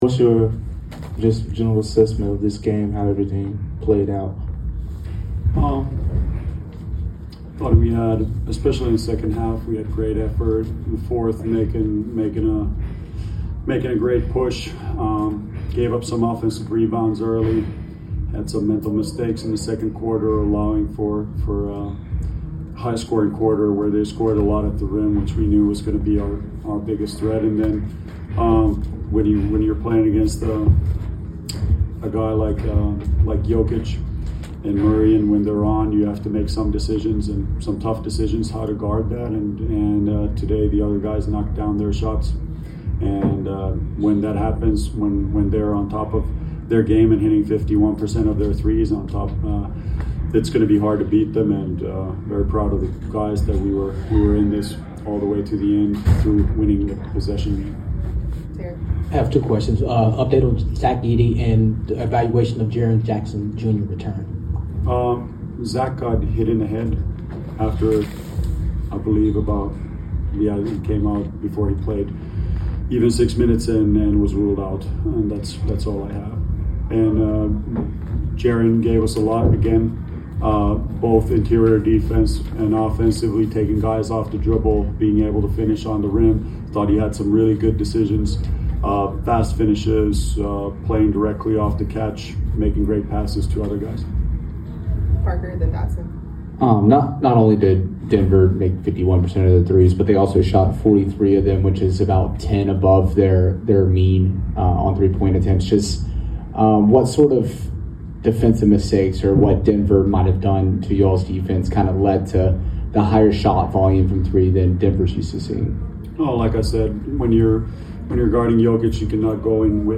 Memphis Grizzlies Coach Tuomas Iisalo Postgame Interview after losing to the Denver Nuggets at FedExForum.